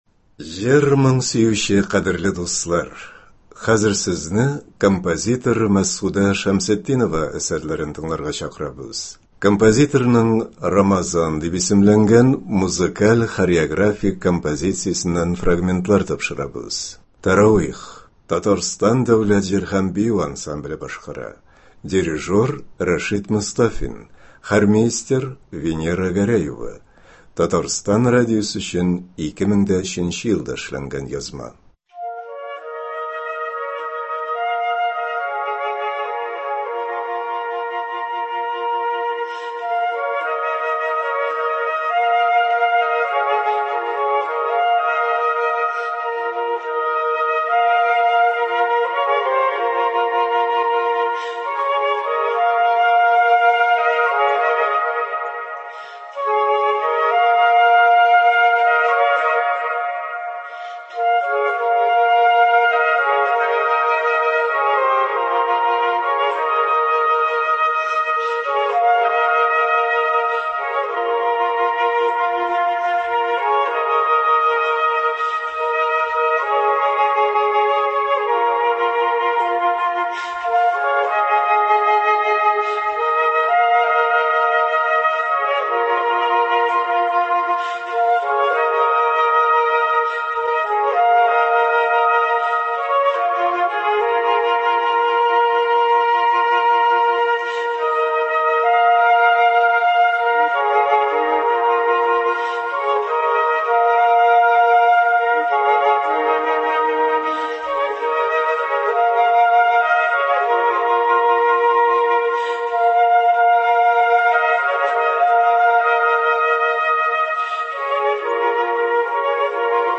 Көндезге концерт.
Мөнәҗәтләр.